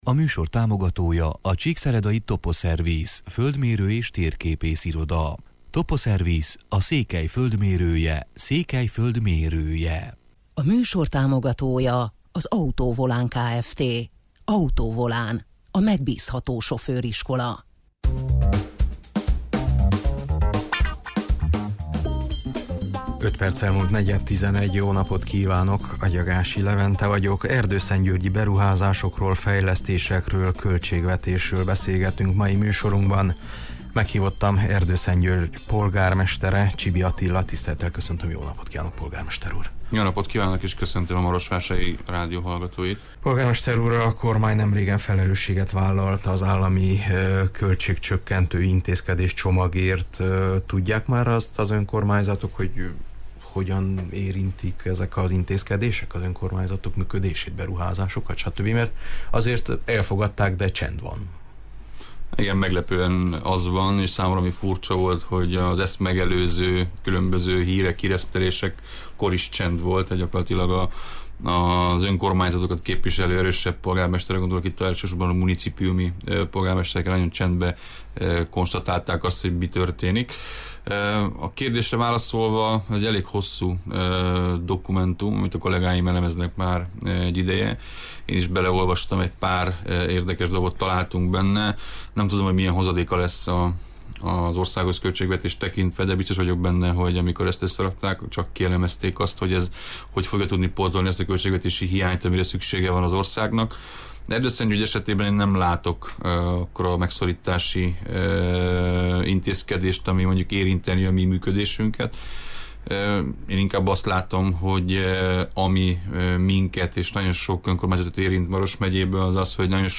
Meghívottam Erdőszentgyörgy polgármestere, Csibi Attila: